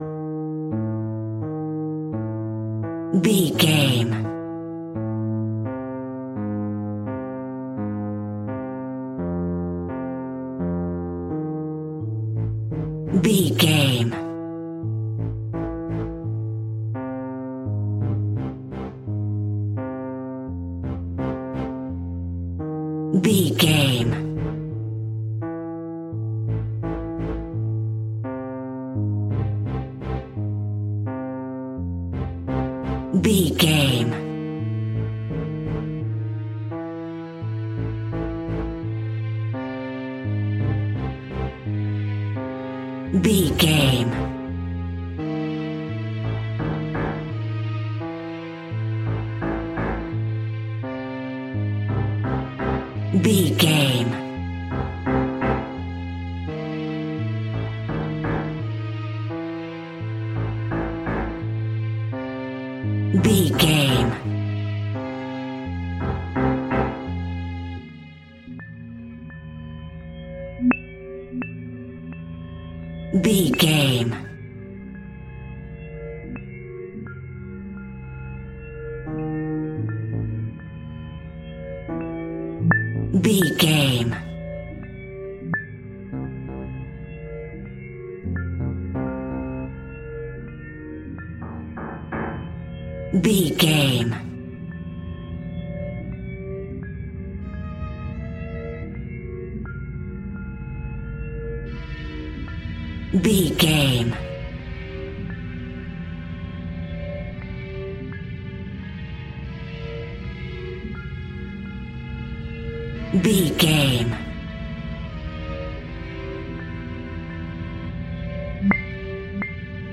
In-crescendo
Aeolian/Minor
Slow
scary
ominous
dark
haunting
eerie
strings
synth
keyboards
ambience
pads